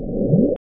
Höllenmaschine, Geräusch, Blubb, Bloop